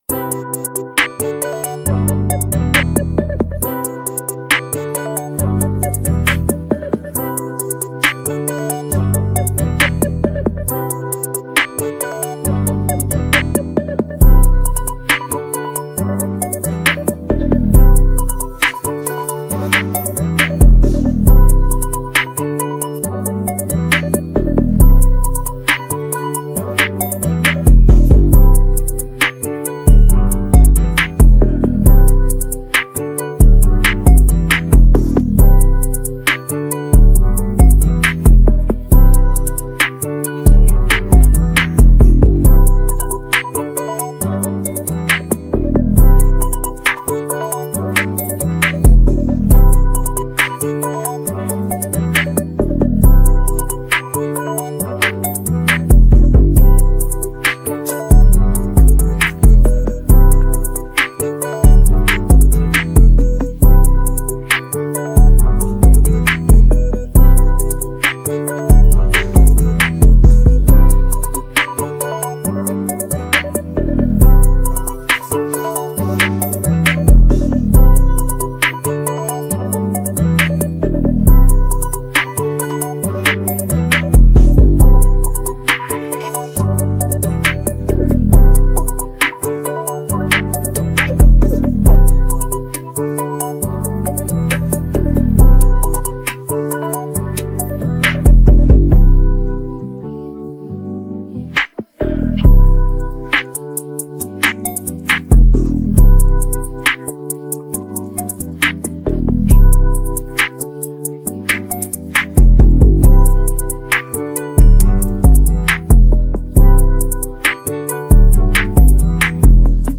Afro-R&B Fusion Pop
Tagged afrobeats